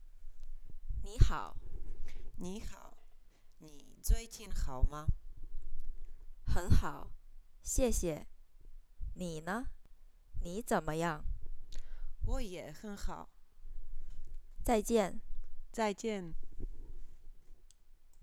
L1_Konverzace_I.wav